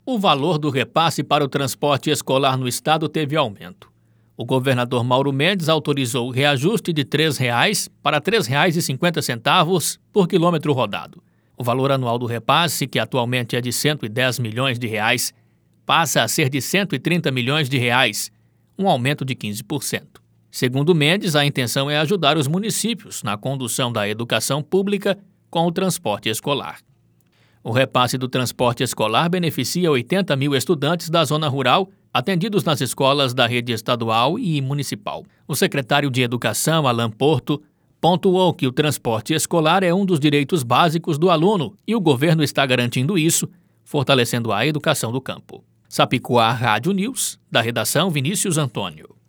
Boletins de MT 10 mar, 2022